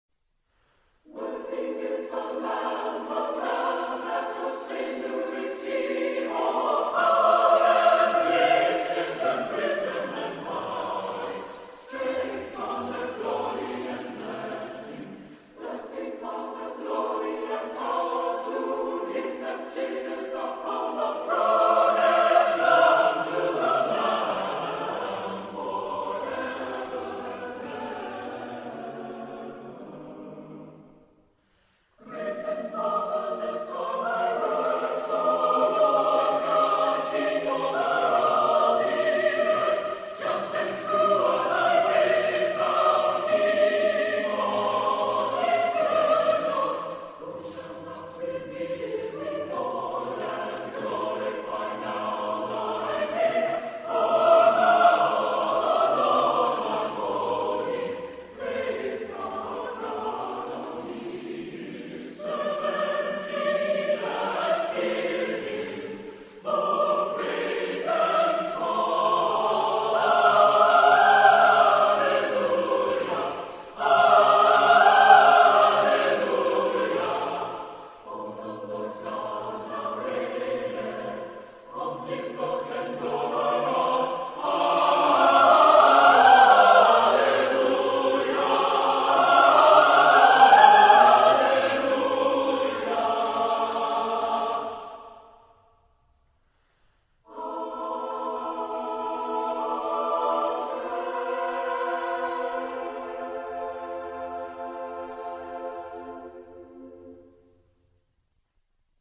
SATB (4 voix mixtes) ; Partition complète.
Sacré. Anthem.
Type de choeur : SATB (4 voix mixtes )